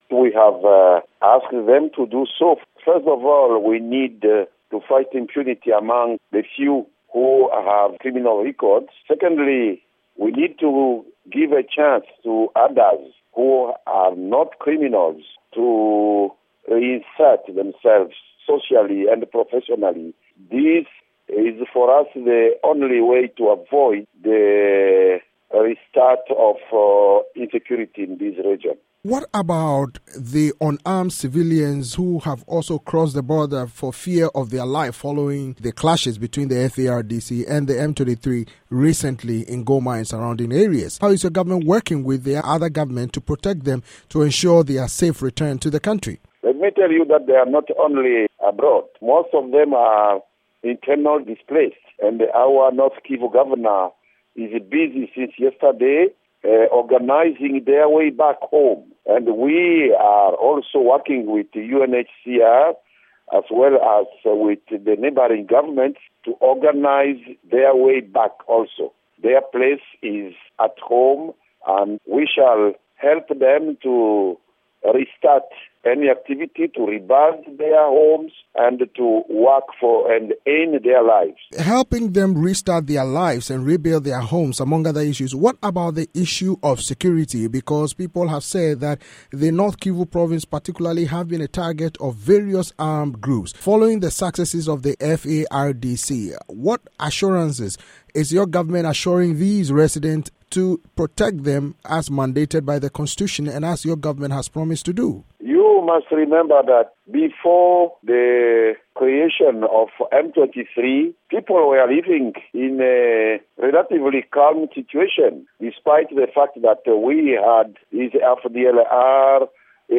interview with Lambert Mende, Congo's information minister